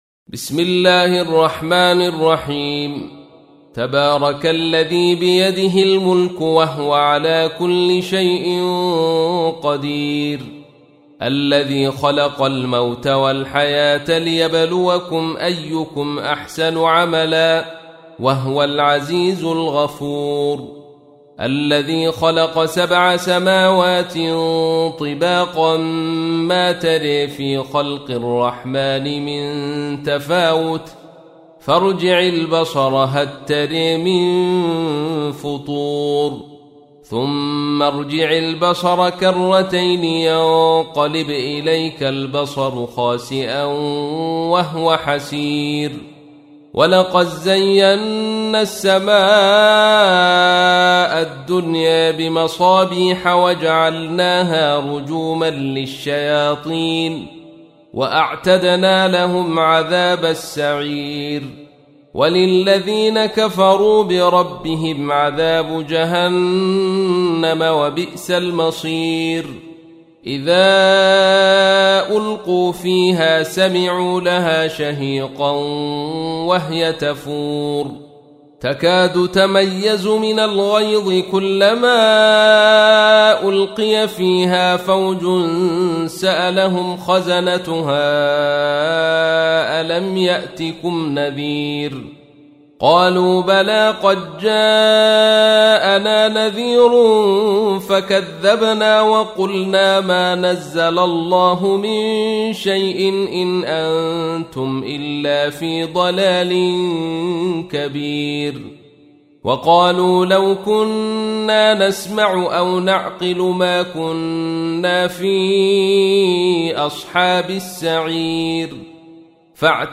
تحميل : 67. سورة الملك / القارئ عبد الرشيد صوفي / القرآن الكريم / موقع يا حسين